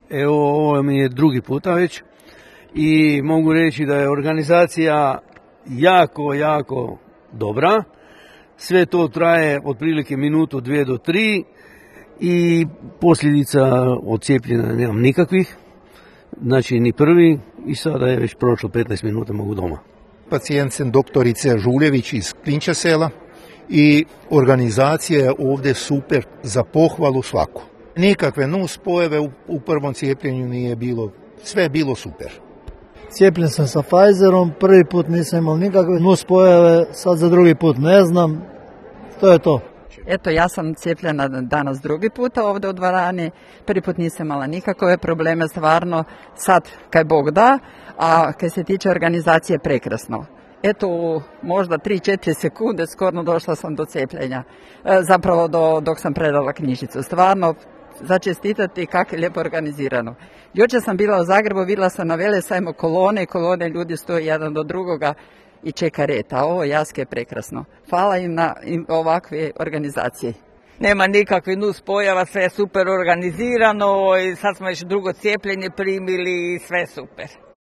Potvrdili su to za naš radio i građani koji su bili na cijepljenju u sportskoj dvorani srednje škole.